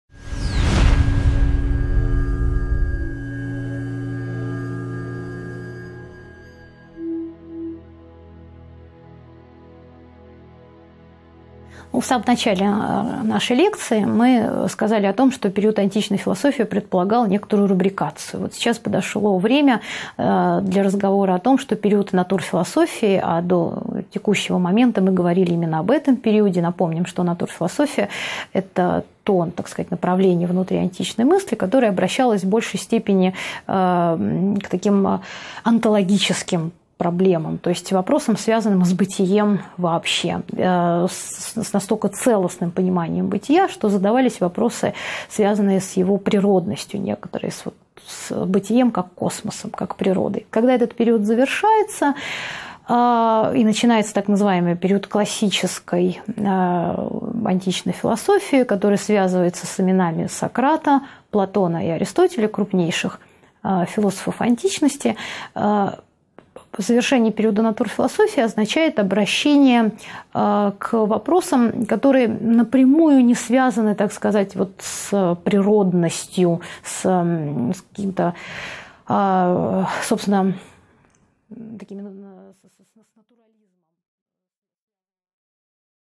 Аудиокнига 2.6 Обращение к идеальному | Библиотека аудиокниг